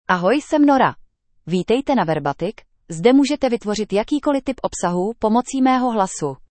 NoraFemale Czech AI voice
Nora is a female AI voice for Czech (Czech Republic).
Voice sample
Listen to Nora's female Czech voice.
Female
Nora delivers clear pronunciation with authentic Czech Republic Czech intonation, making your content sound professionally produced.